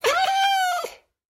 Minecraft Version Minecraft Version 1.21.5 Latest Release | Latest Snapshot 1.21.5 / assets / minecraft / sounds / mob / cat / ocelot / death2.ogg Compare With Compare With Latest Release | Latest Snapshot
death2.ogg